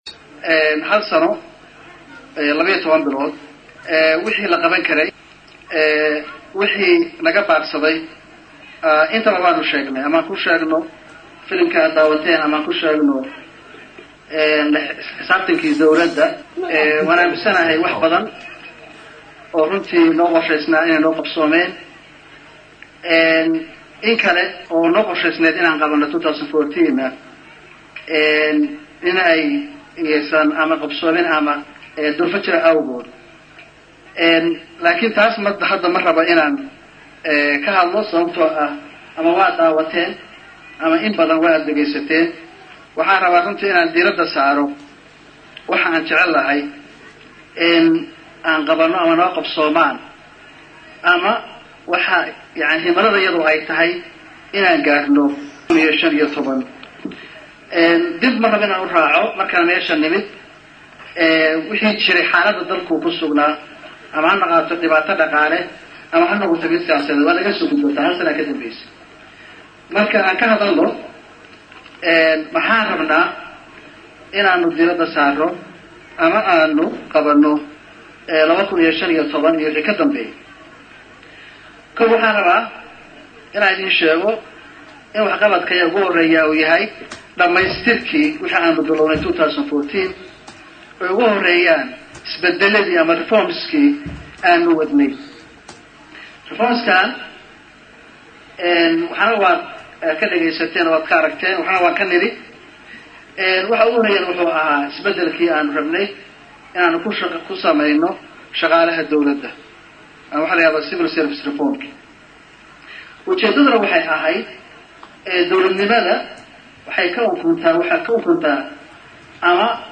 Jimco, Janaayo 09, 2015 (HOL) — Madaxweynaha Puntland, C/weli Maxamed Cali Gaas oo xalay munaasabad lagu xusayay sannadguuradii koowaad ee kasoo wareegtay markii la doortay oo madaxtooyada Puntland ee Garoowe lagu qabtay ayaa khudbad uu ka jeediyay kaga hadlay wax-qabadkii xukuumaddiisa.
DHAGEYSO: Khudbaddii uu jeediyay Madaxweyaha Puntland, C/weli Gaas Sidoo kale, wuxuu intaa ku daray.
DHAGEYSO_Khudbaddii_Madaxweynaha_Puntland_HOL.mp2